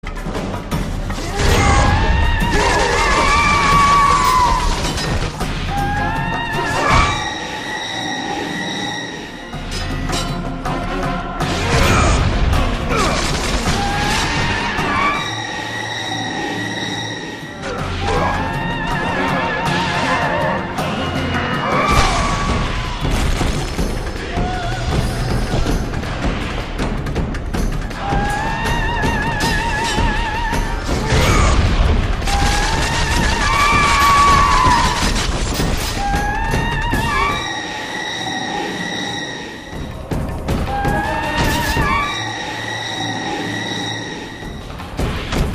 Voice over Siren voice and song